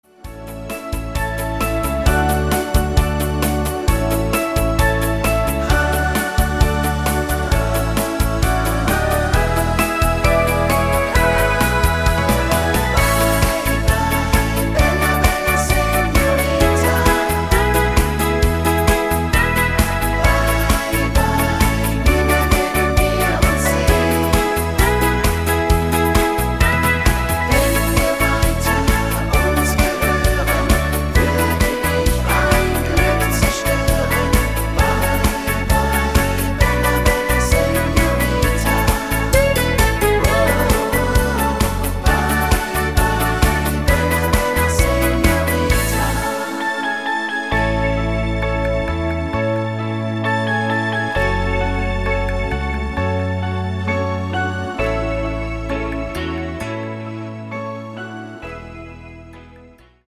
Rhythmus  Medium 8 Beat